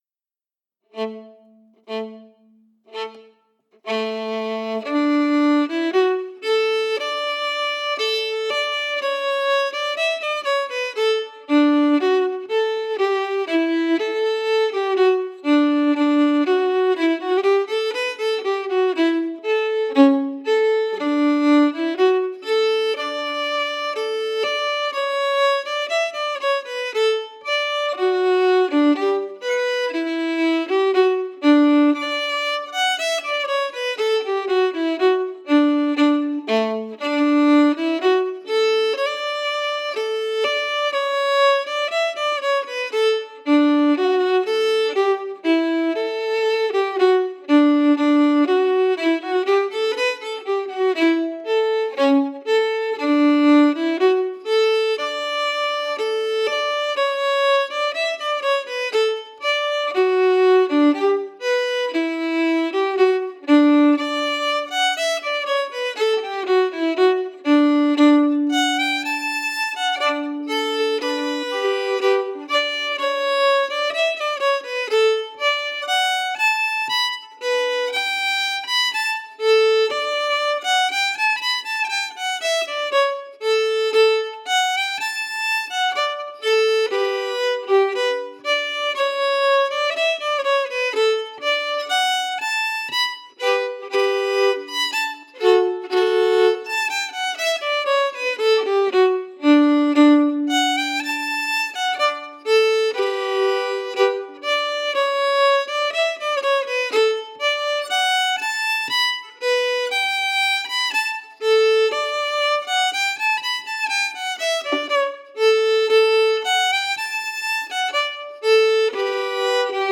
Key: D
Form: March
Slow for learning
Region: Shetland